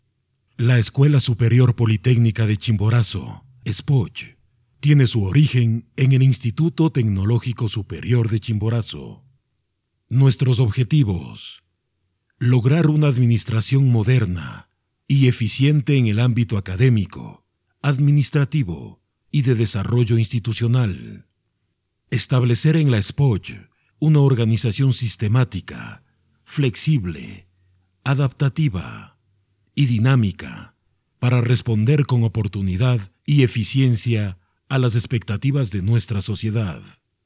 Barítono bajo, con acento neutro, que puede alcanzar figuras detalladas en el romance o en la narración.
Sprechprobe: eLearning (Muttersprache):